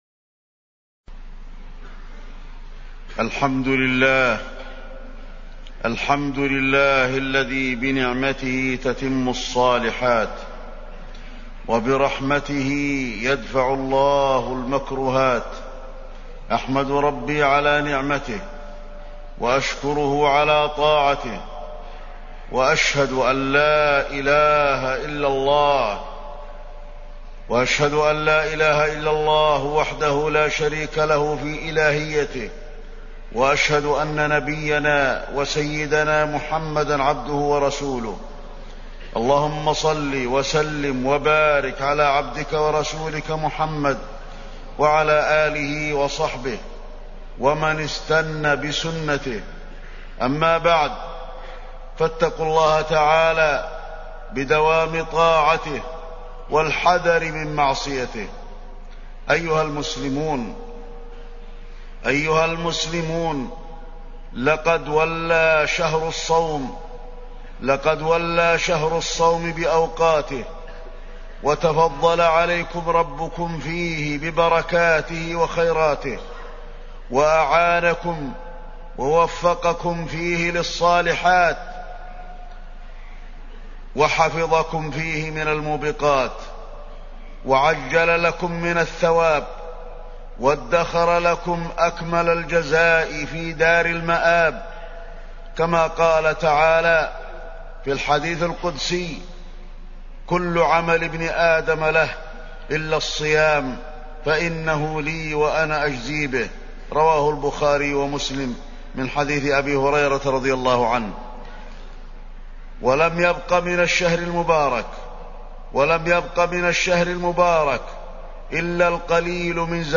تاريخ النشر ٢٧ رمضان ١٤٢٧ هـ المكان: المسجد النبوي الشيخ: فضيلة الشيخ د. علي بن عبدالرحمن الحذيفي فضيلة الشيخ د. علي بن عبدالرحمن الحذيفي وداع رمضان والحث على المداومة على الأعمال